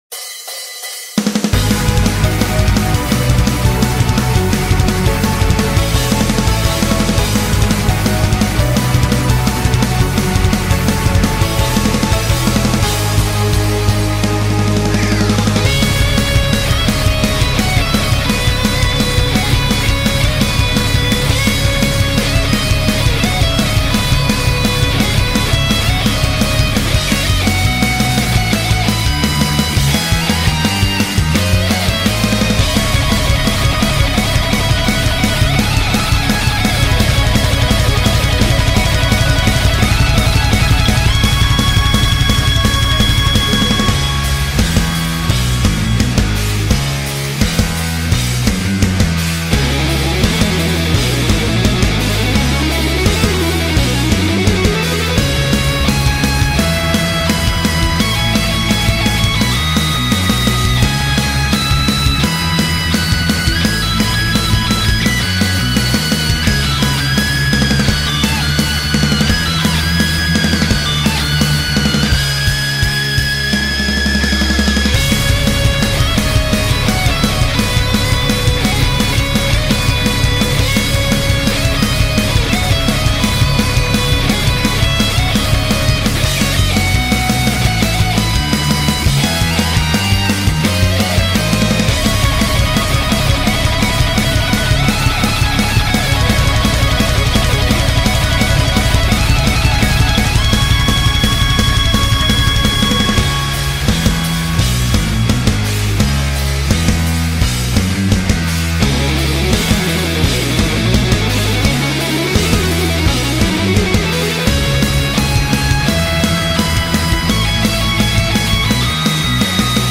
Rock/Metal Cover